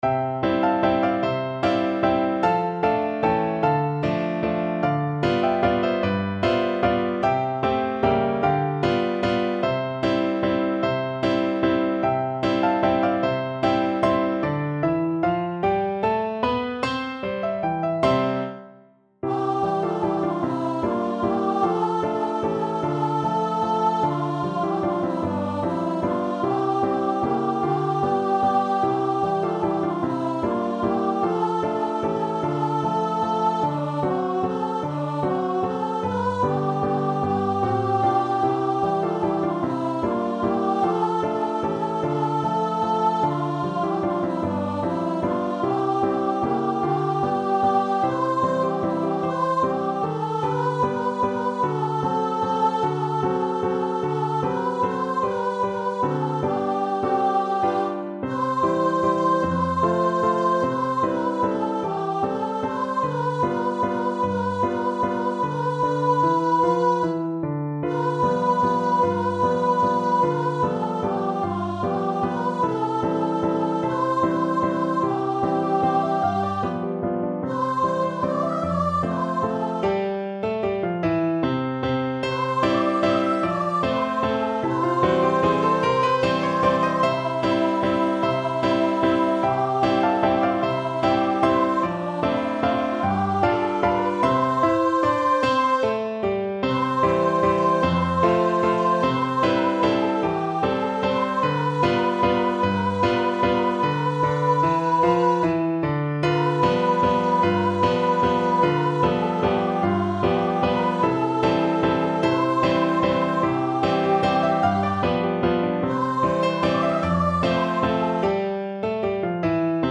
~ = 150 Tempo di Valse
Classical (View more Classical Voice Music)